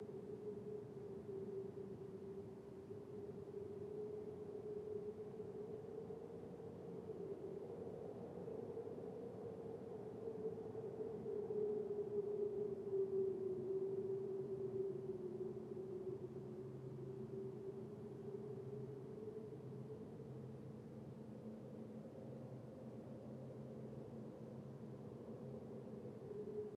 wind-aquilo.ogg